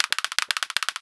bot_climb.wav